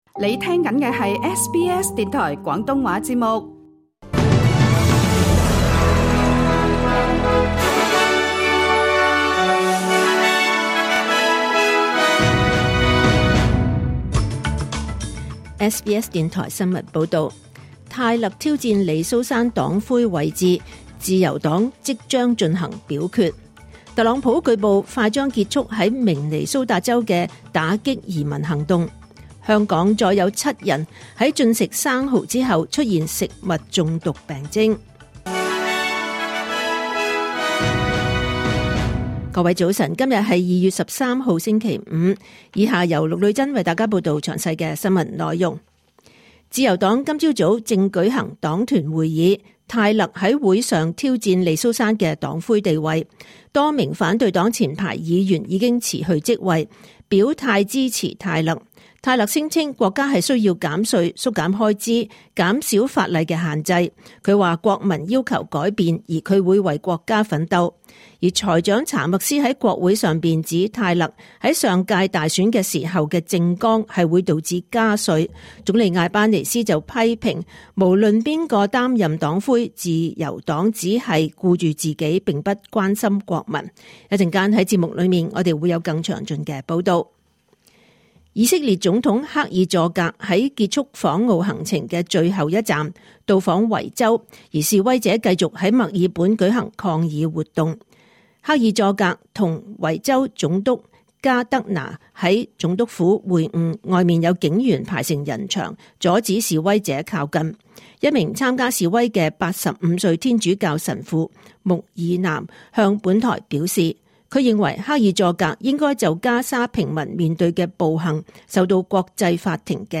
2026 年 2 月 13 日SBS廣東話節目九點半新聞報道。